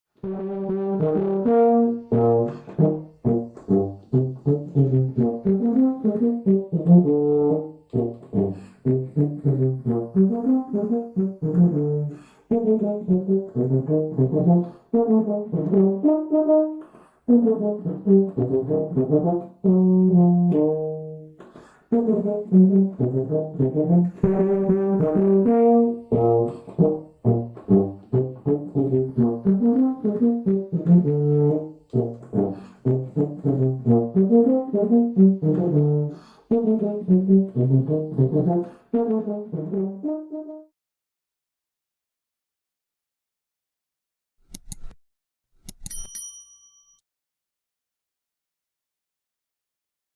Sonido+De+Tuba (audio/mpeg)
TUBA familia: viento metal